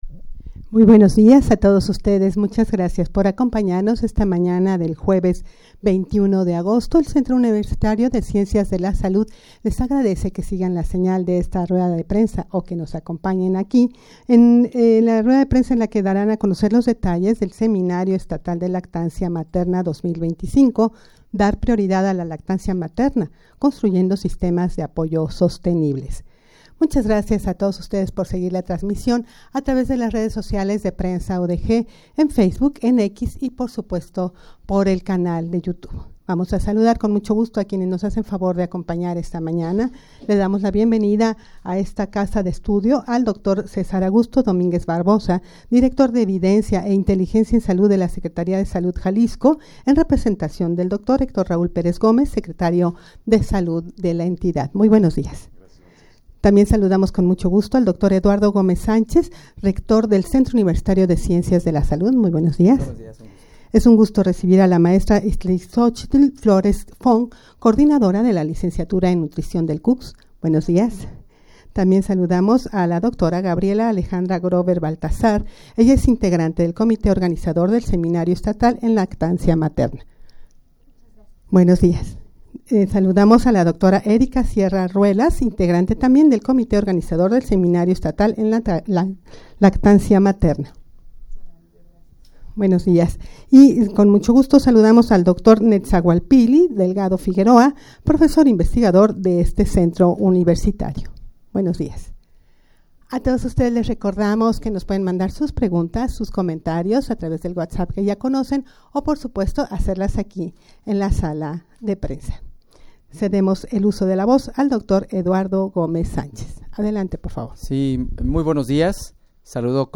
Audio de la Rueda de Prensa
rueda-de-prensa-en-la-que-se-daran-a-conocer-los-detalles-del-seminario-estatal-de-lactancia-materna-2025.mp3